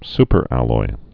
su·per·al·loy
(spər-ăloi)